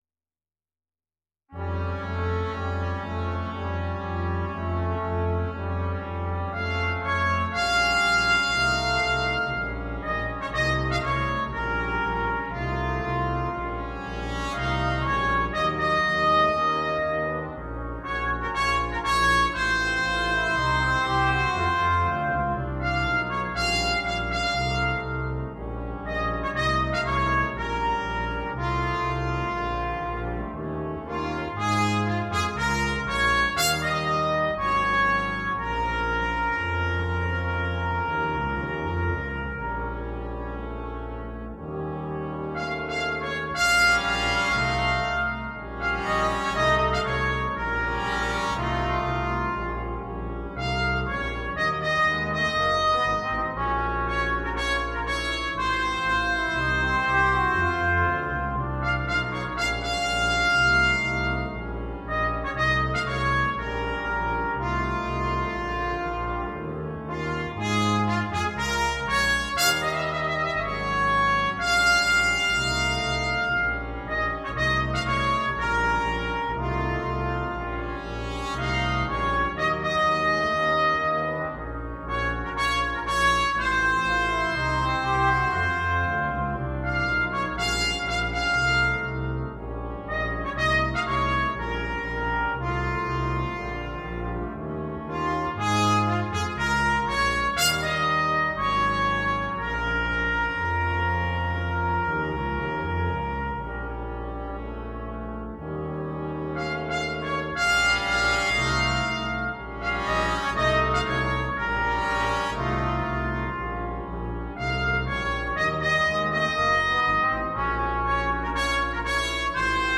для брасс-бэнда.
• состав: Trumpet in B 1, Trumpet in B 2,  Trombone, Tuba.